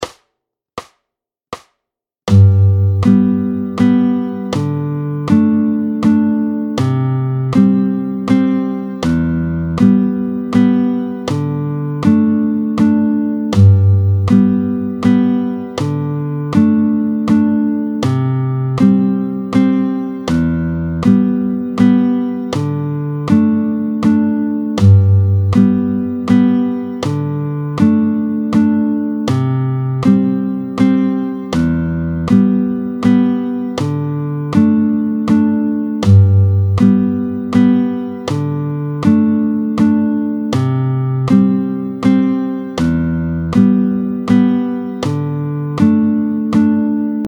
07-03 La mesure à 3/4 (3 temps), tempo 80